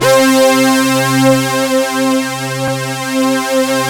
Index of /90_sSampleCDs/Sound & Vision - Gigapack I CD 2 (Roland)/SYN_ANALOG 1/SYN_Analog 2